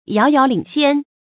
遥遥领先 yáo yáo lǐng xiān 成语解释 遥遥：远远；很远。